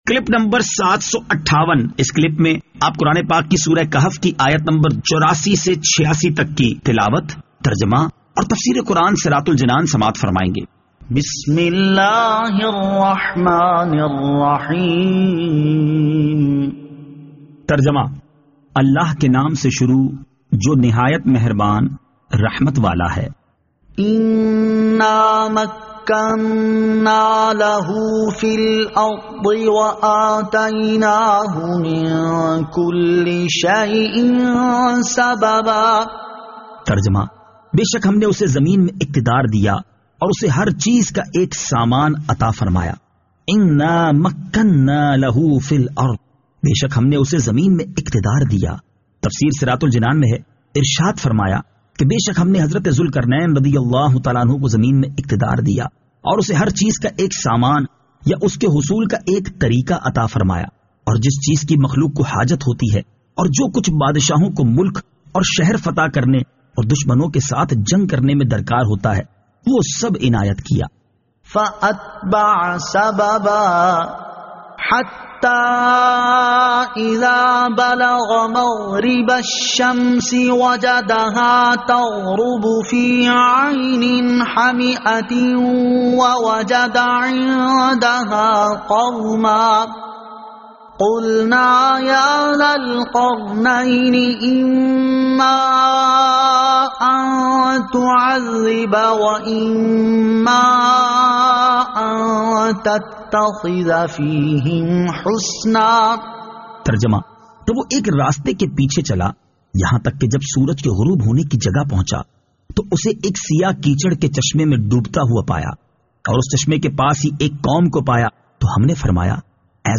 Surah Al-Kahf Ayat 84 To 86 Tilawat , Tarjama , Tafseer